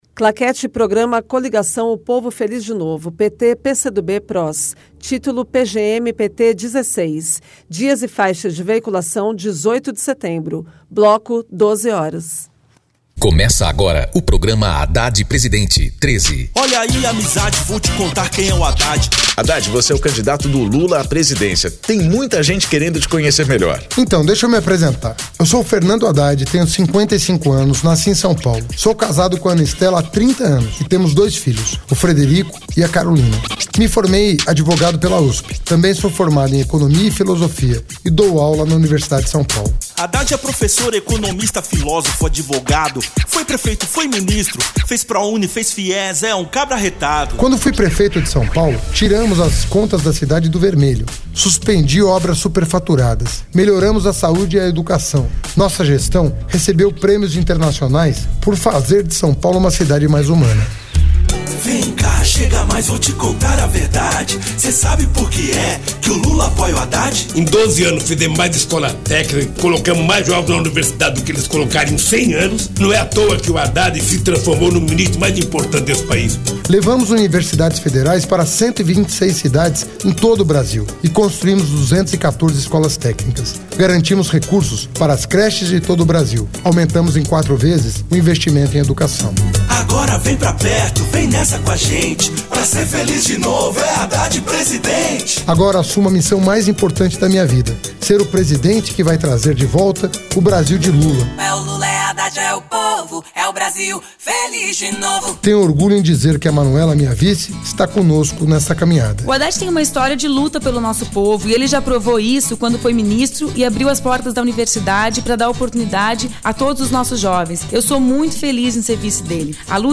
TítuloPrograma de rádio da campanha de 2018 (edição 16)
Gênero documentaldocumento sonoro